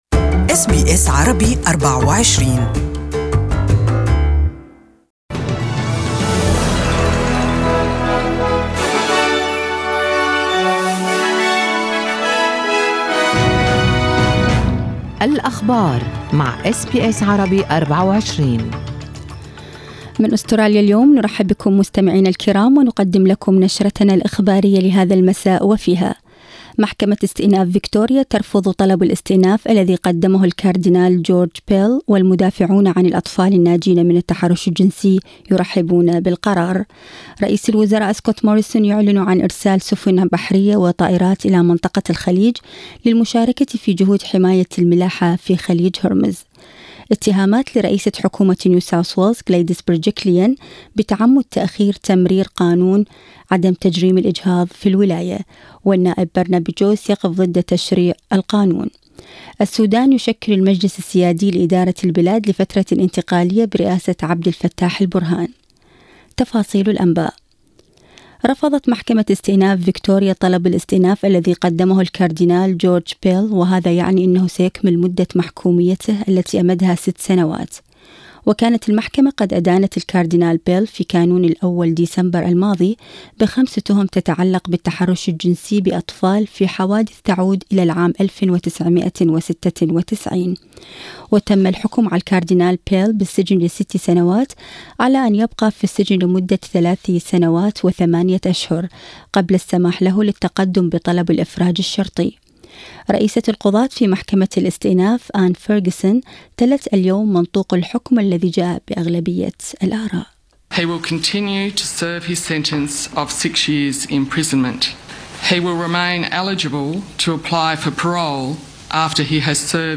أخبار المساء: مظاهرات مع وضد قانون الإجهاض في نيو ساوث ويلز